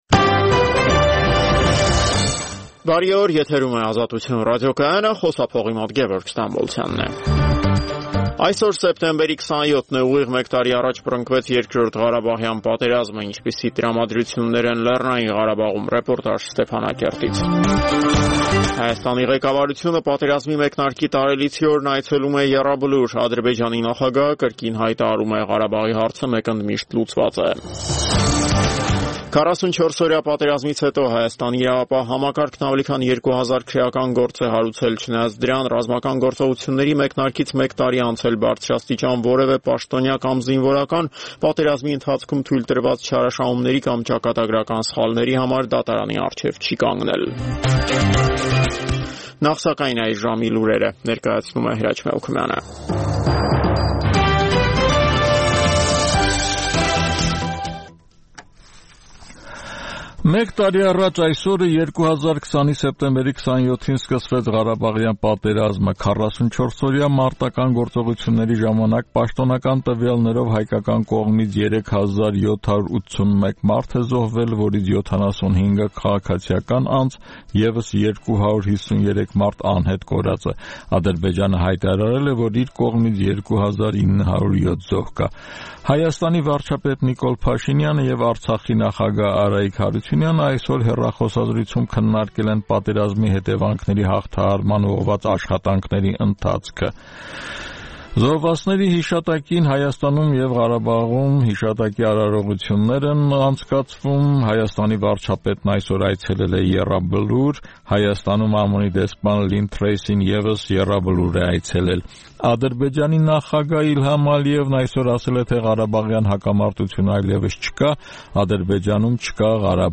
Տեղական եւ միջազգային լուրեր, ռեպորտաժներ, հարցազրույցներ: